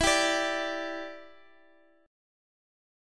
G5/E chord
G-5th-E-x,x,x,9,8,10.m4a